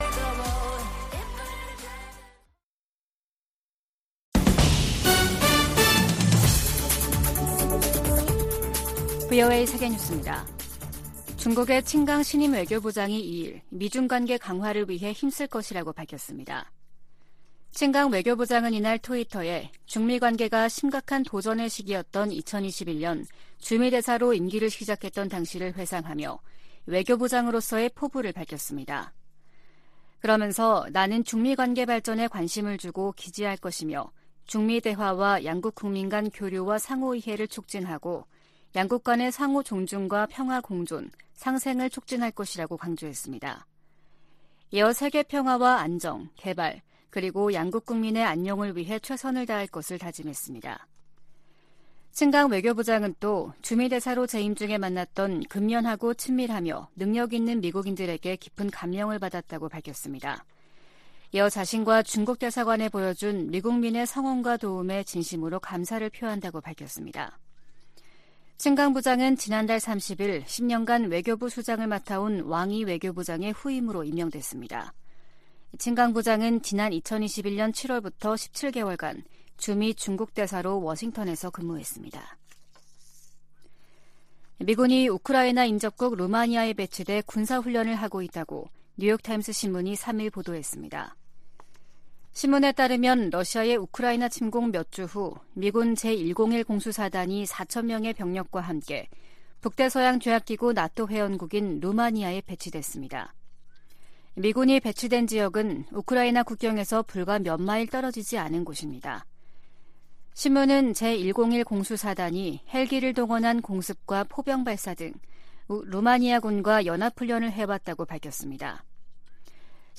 VOA 한국어 아침 뉴스 프로그램 '워싱턴 뉴스 광장' 2023년 1월 4일 방송입니다. 유럽연합과 영국, 캐나다 등이 북한에 군사적 긴장 고조행위를 중단하고 비핵화 대화에 복귀하라고 촉구했습니다. 조 바이든 미국 대통령에게는 북한 핵 문제를 비롯한 세계적인 핵무기 위험이 새해에도 주요 외교적 도전이 될 것이라고 미국 외교전문지가 지적했습니다.